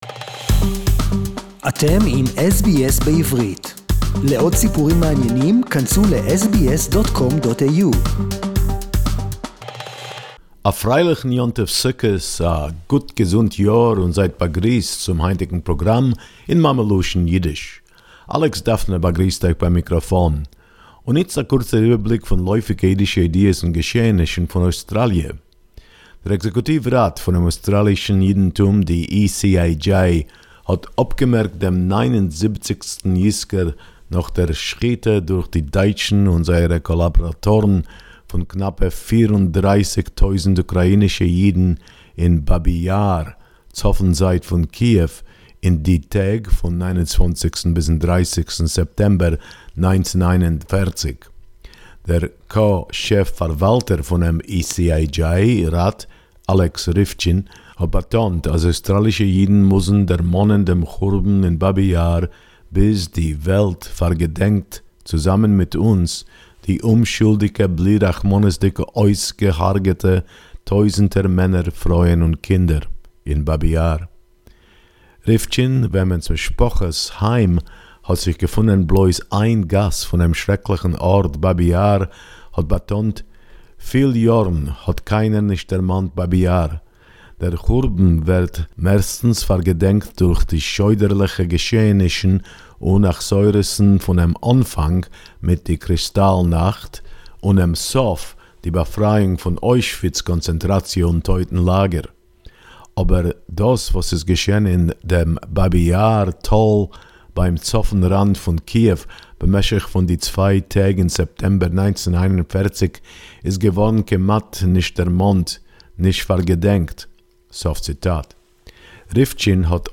The anniversary of the Nazi murder of nearly 34 thousand Jews at Babi Yar Yiddish report 4.10.2020